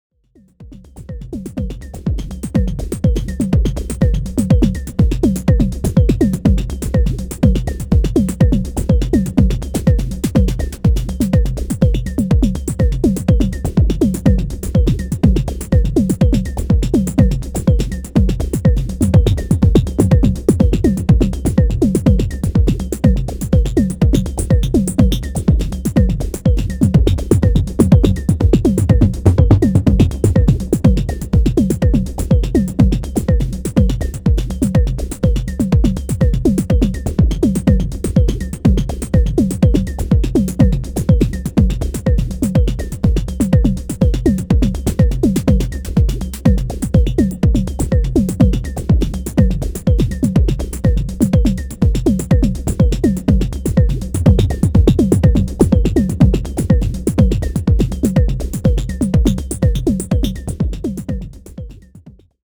Just connected it to ableton for the first time and multitracked x3 sequences over a 909 kick if anyone wants to listen: